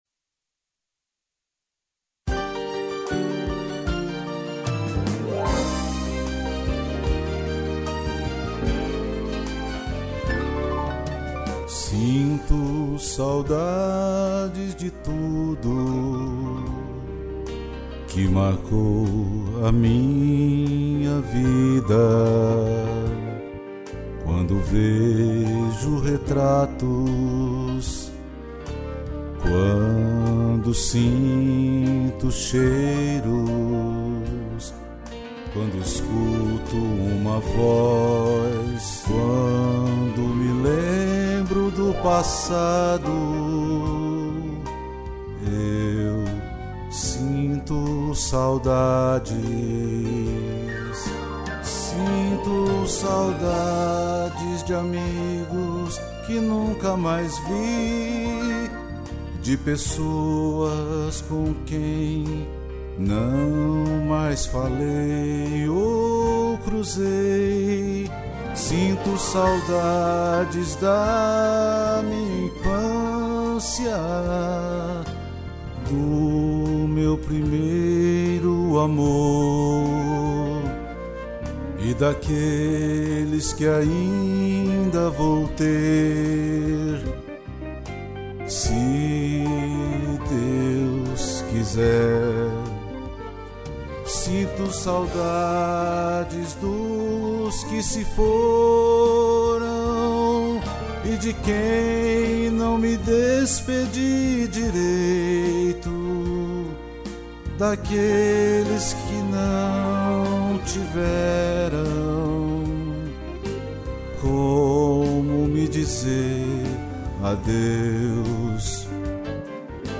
piano, flauta e tutti